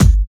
12 KICK.wav